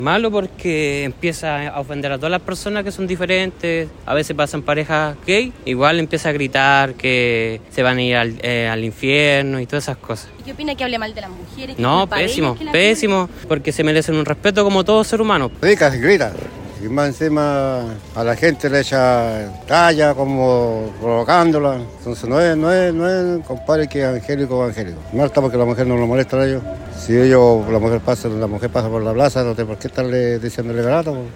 Entre las personas que a diario ocupan la plaza, consultados, también condenaron este hecho y señalaron que este supuesto pastor llega todos los días en la tarde con su parlante y ataca a personas que pasan por el lugar.